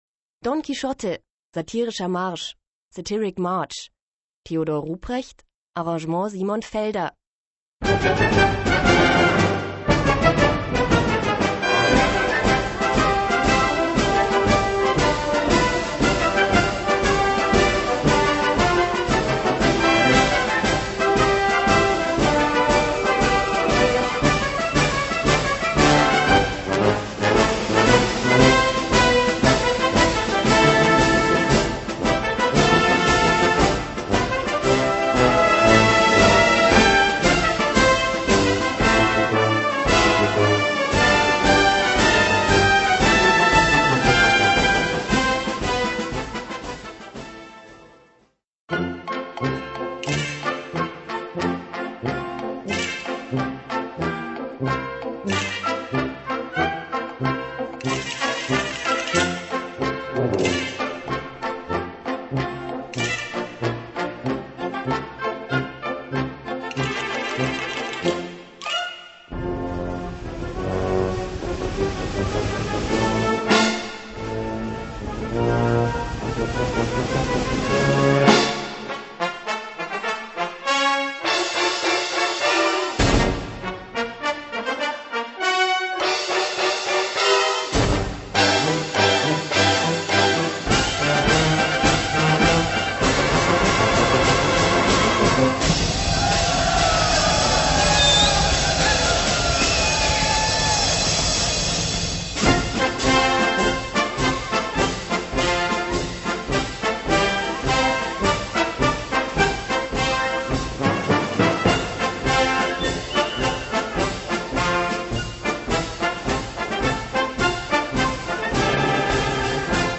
Gattung: Satirischer Marsch
Besetzung: Blasorchester